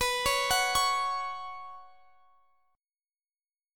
Listen to Bsus2 strummed